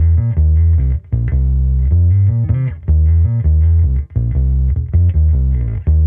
Index of /musicradar/sampled-funk-soul-samples/79bpm/Bass
SSF_PBassProc2_79C.wav